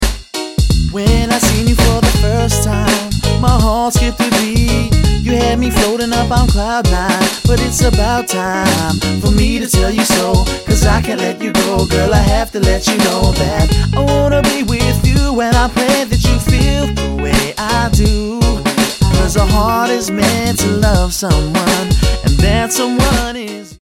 Genre: Contemporary, world music.
The sound is a fusion of blues, ska, soul.